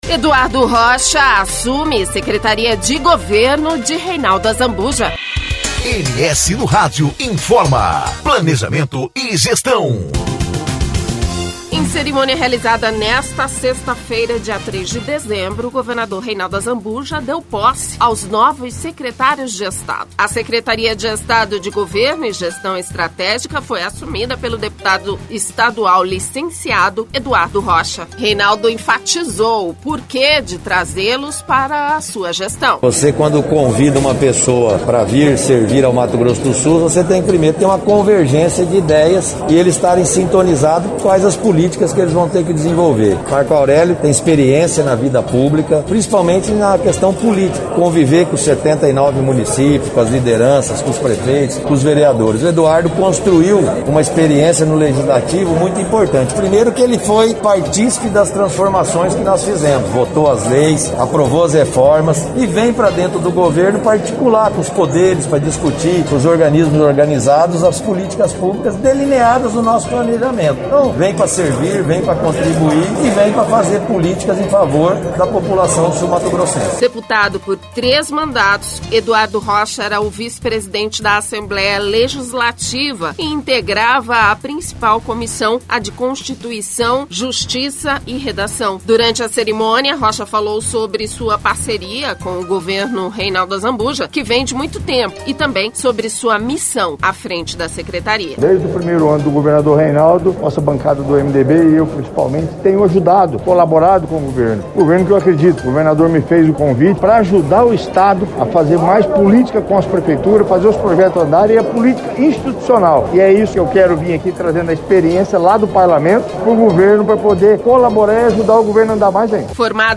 Durante a cerimônia, Rocha falou sobre a parceria com o governo Reinaldo Azambuja que vem de muito tempo, e também sobre sua missão à frente da Secretaria.